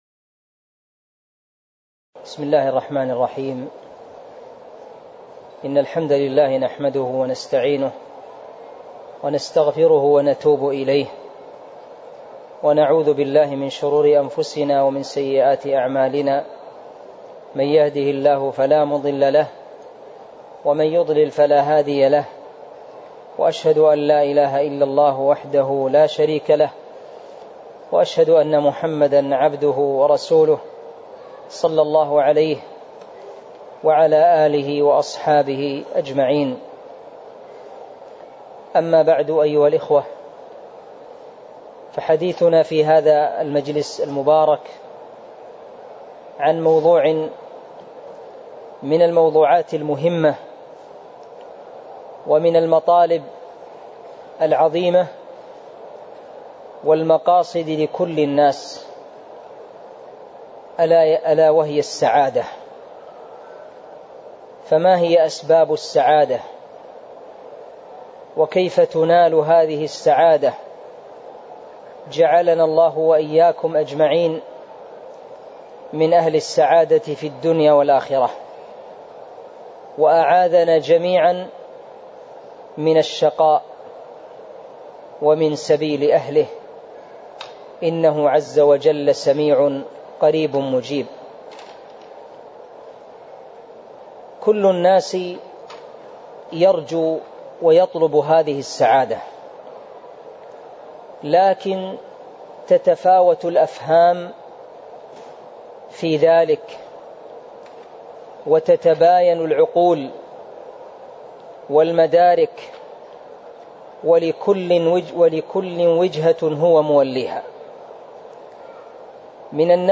تاريخ النشر ٢٠ محرم ١٤٤٥ هـ المكان: المسجد النبوي الشيخ